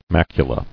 [mac·u·la]